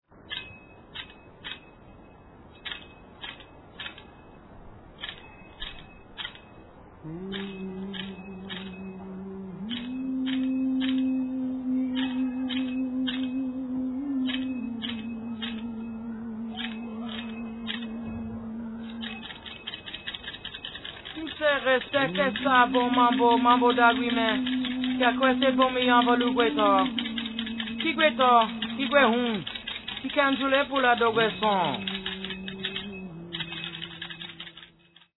Afro-Caribbean percussionist
Digitally recorded and produced in the studio
Afro-Haitian percussion